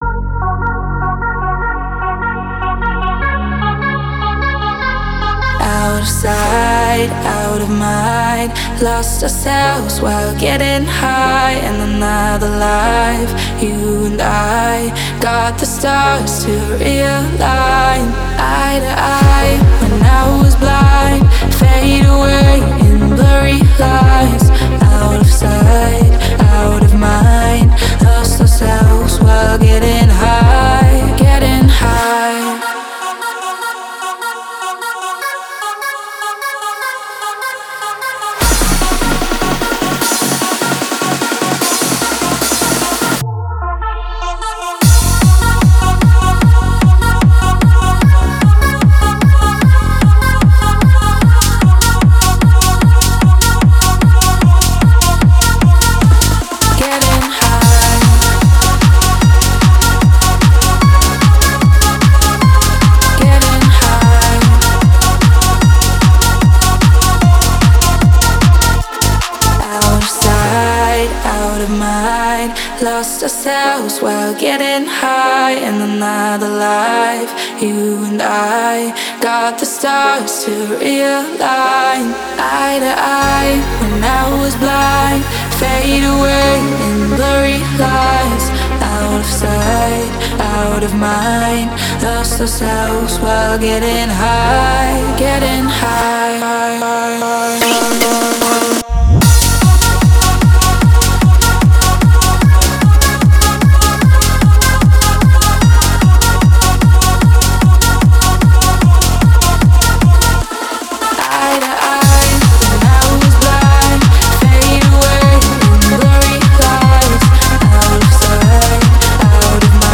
Клубная музыка
клубные треки